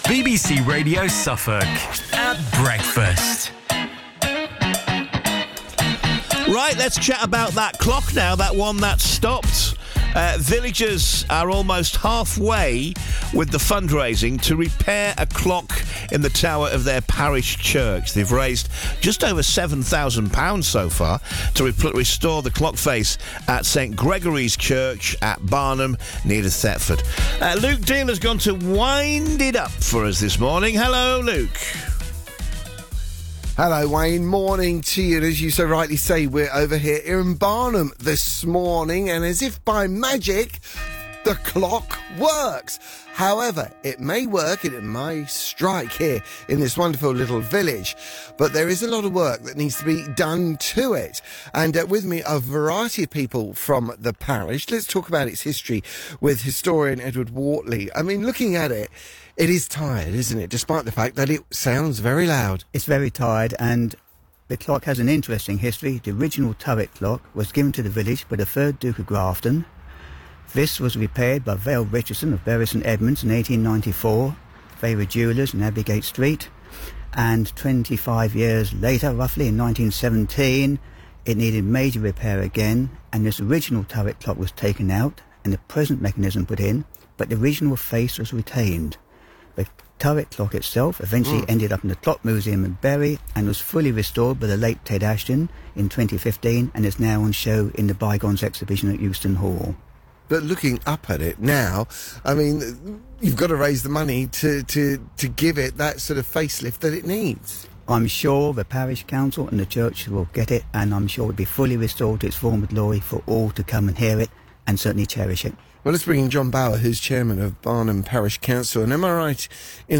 Interview With Radio Suffolk - September 2024
Barnham-Clock-Interview.mp3